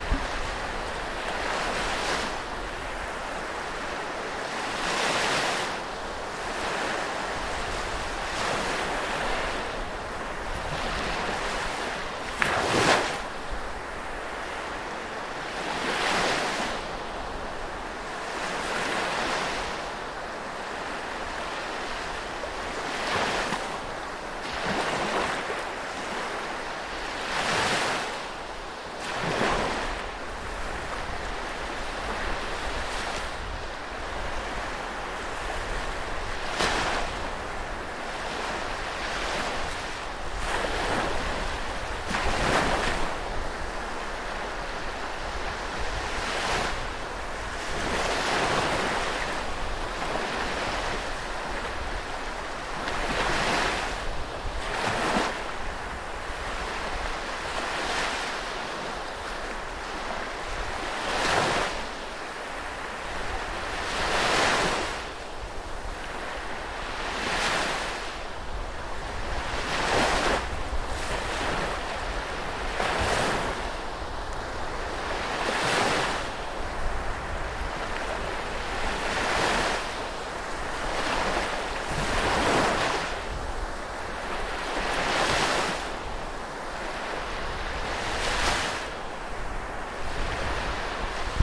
海洋
描述：岸的波罗的海在德国。用Zoom H1 WAV 44khz 16bit立体声录制
标签： 波罗的海 岸边 现场 记录